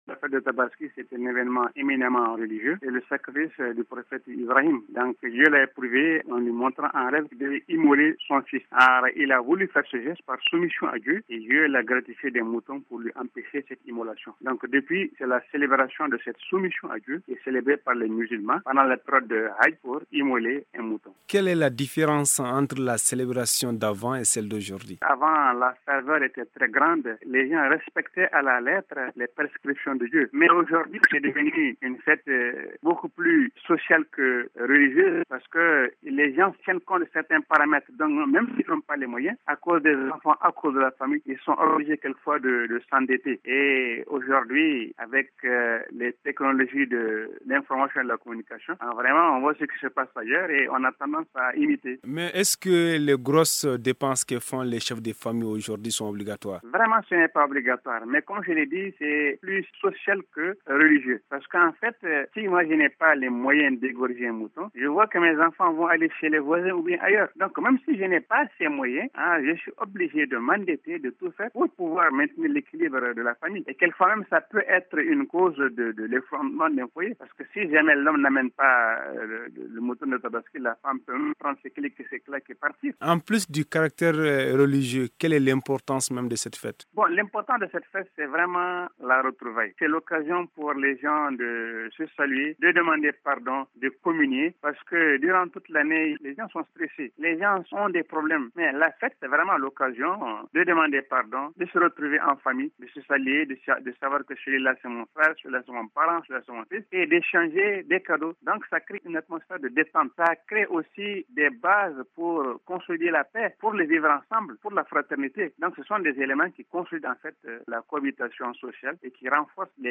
joint au téléphone